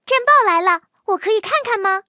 T43查看战绩语音.OGG